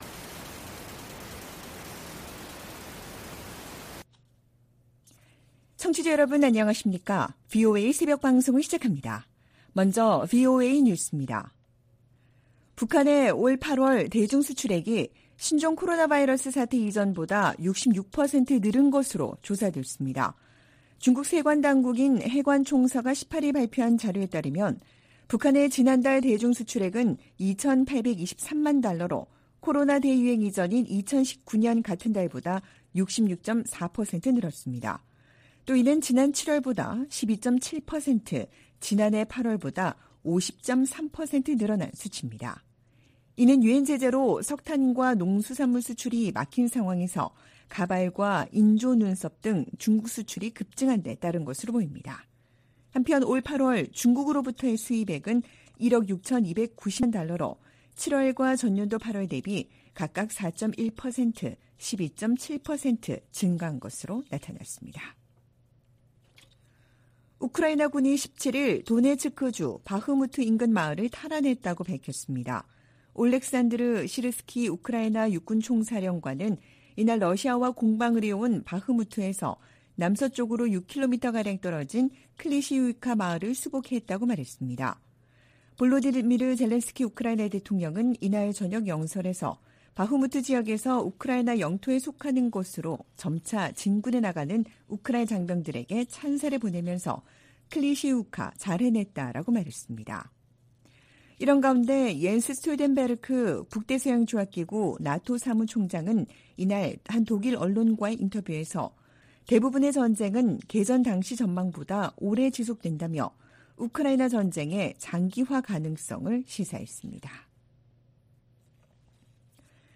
VOA 한국어 '출발 뉴스 쇼', 2023년 9월 19일 방송입니다. 백악관은 북한과 러시아 사이에 무기 제공 논의가 계속 진전되고 있으며 예의 주시하고 있다고 밝혔습니다. 윤석열 한국 대통령은 북-러 군사협력 움직임에 대해 유엔 안보리 결의에 반한다며 한반도 문제 해결을 위한 중국의 역할을 거듭 촉구했습니다. 우크라이나 주변국들은 북한이 러시아에 우크라이나 전쟁에 필요한 무기를 제공 중이라는 주장에 촉각을 곤두세우고 있습니다.